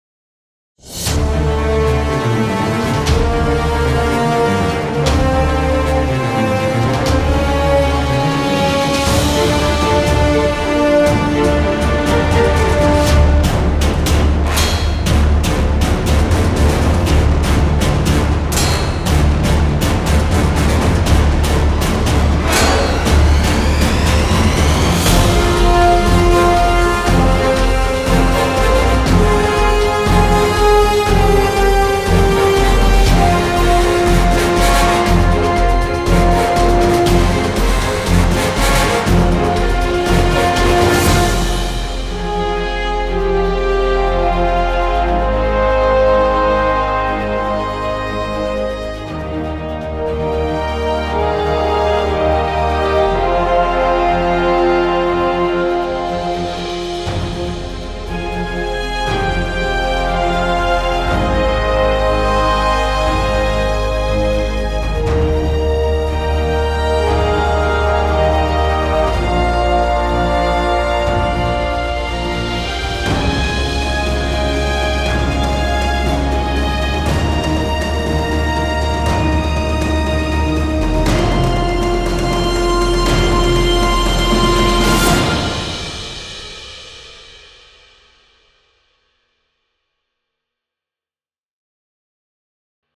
これが本物のハリウッドサウンドです。